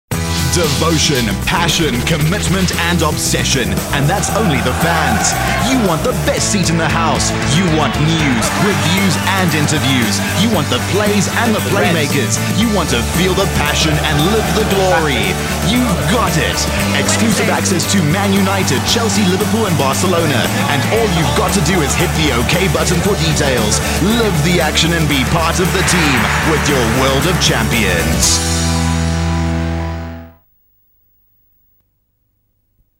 South African English Speaker with Neutral Accent.
Sprechprobe: Sonstiges (Muttersprache):